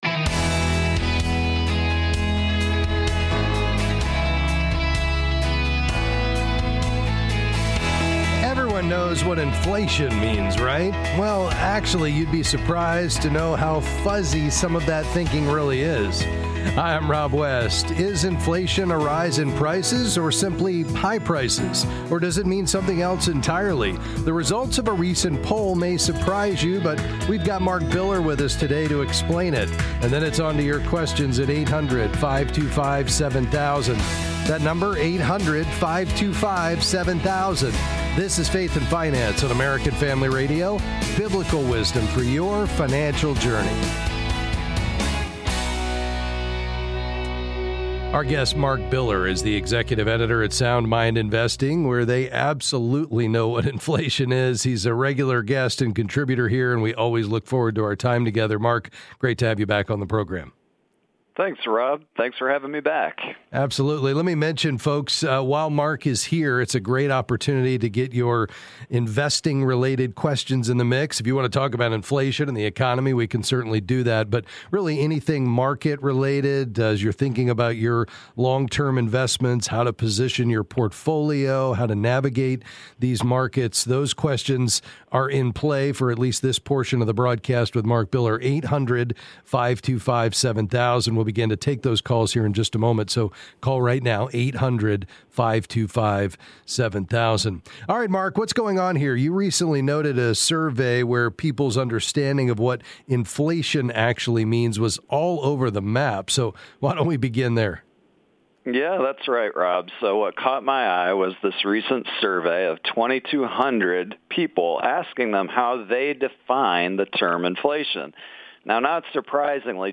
Then they answer calls about investing.